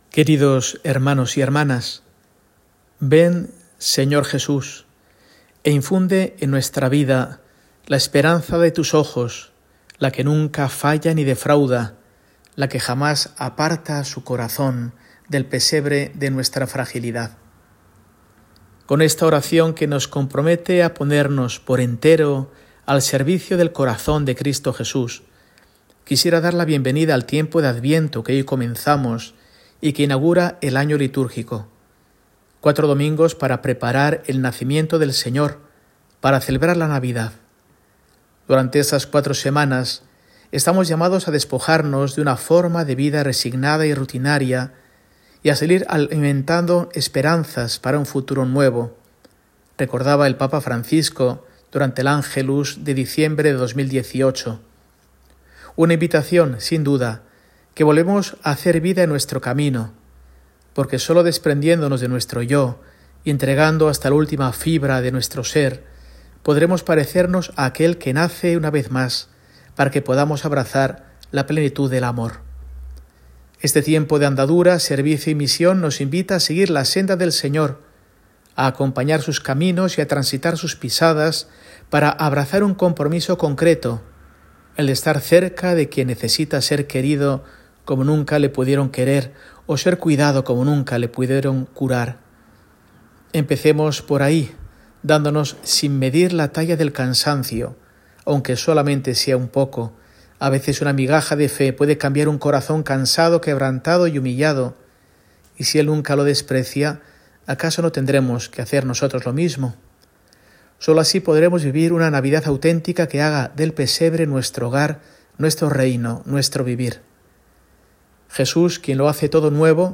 Mensaje semanal de Mons. Mario Iceta Gavicagogeascoa, arzobispo de Burgos, para el domingo, 1 de diciembre, I Domingo de Adviento